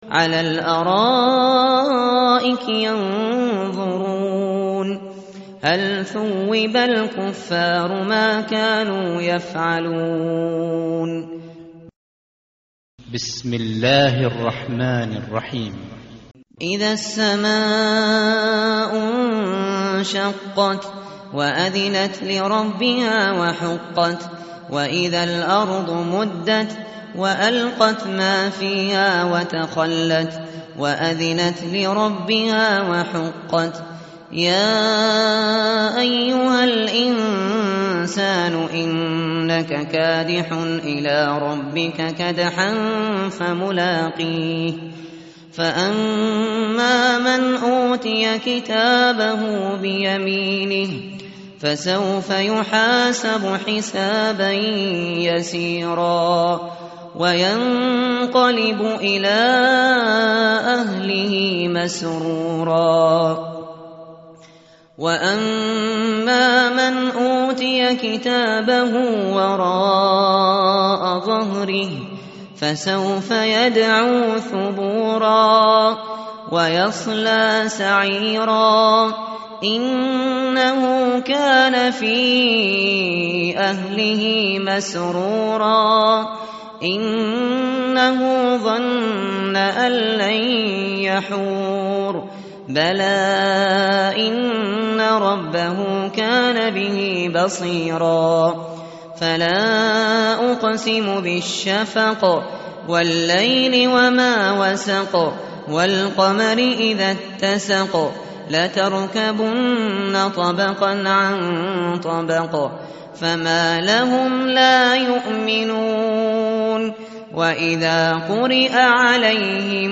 متن قرآن همراه باتلاوت قرآن و ترجمه
tartil_shateri_page_589.mp3